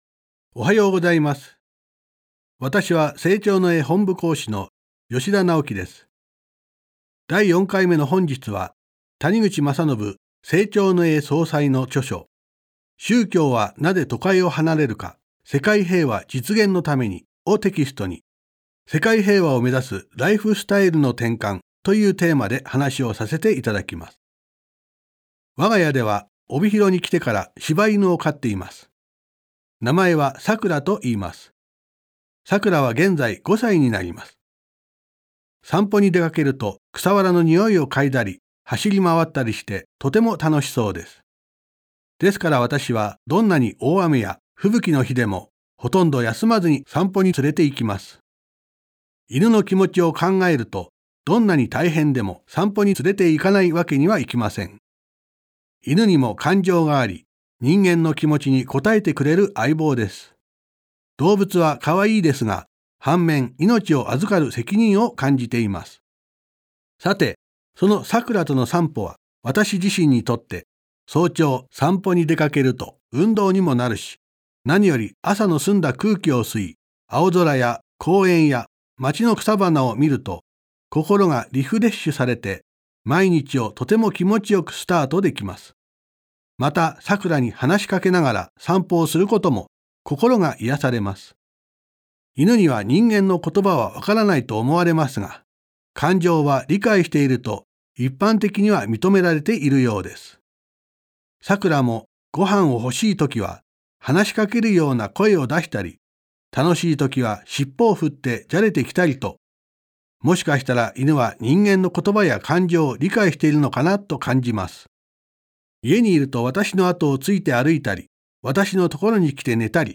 生長の家がお届けするラジオ番組。
生長の家の講師が、人生を豊かにする秘訣をお話しします。